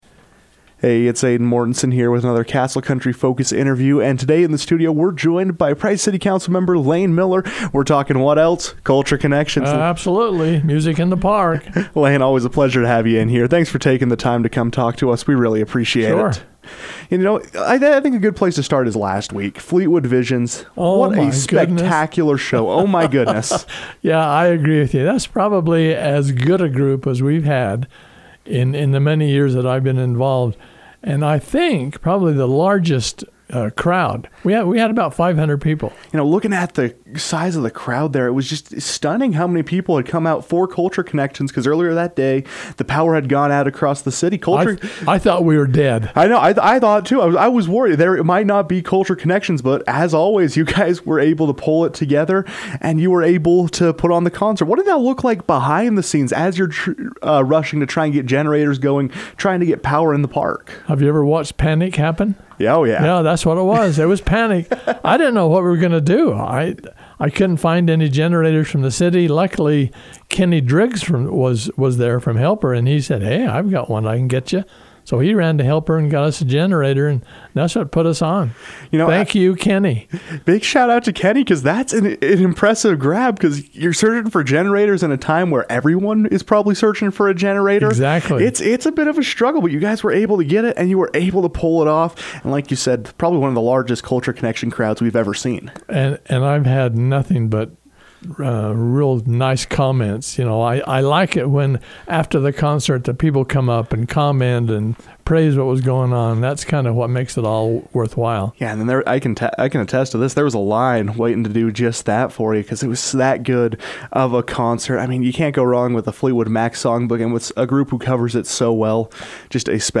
Price City Council Member Layne Miller joined the KOAL newsroom to discuss last week’s success and preview what music lovers can expect on Thursday, July 17.